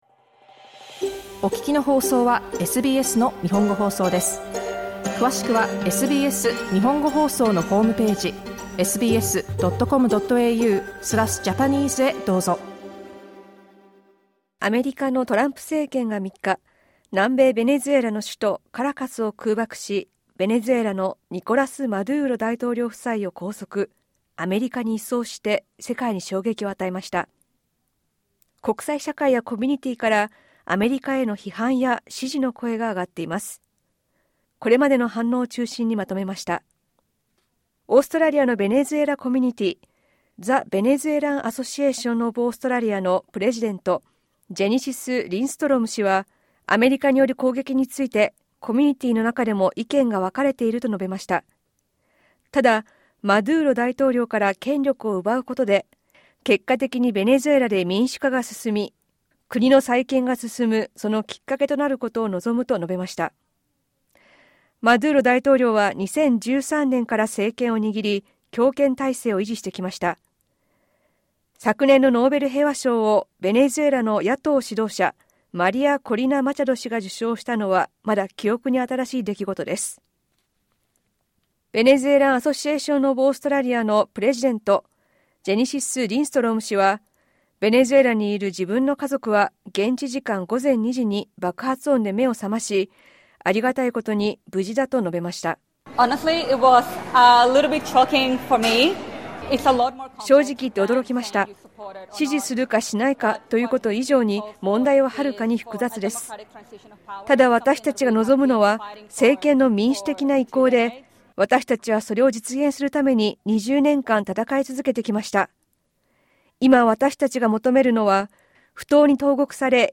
The sentiments of Australia's Venezuelan community, which has long called for a democratic transition of power, vary. A brief report quoting comments to explain the situation.